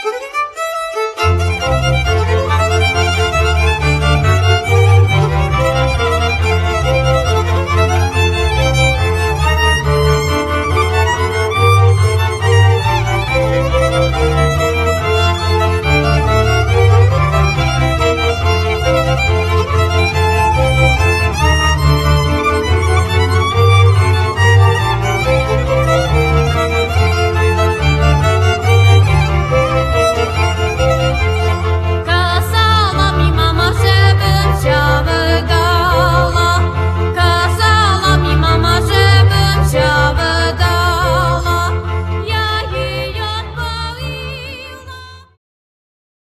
skrzypce, altówki, ¶piew solo violin, violas, solo vocals
kontrabas doublebass